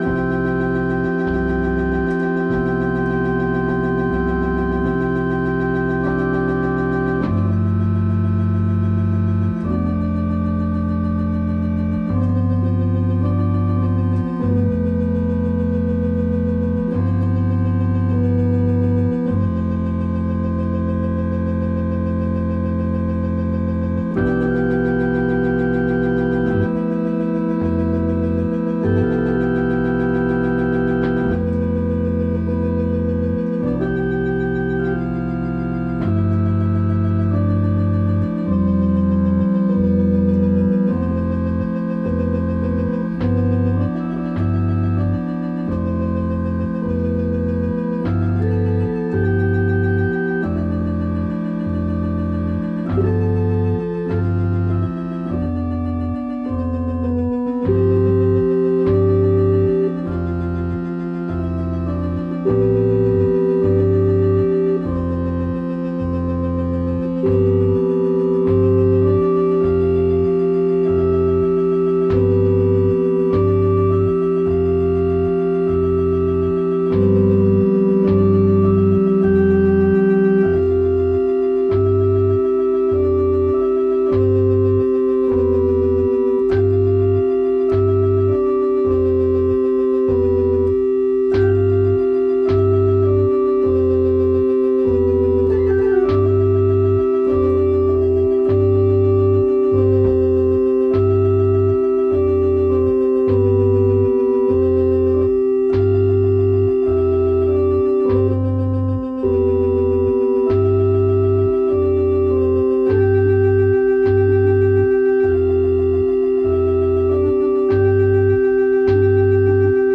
Noisy and sloppy but you might be able to hear some of the goodness hidden in there.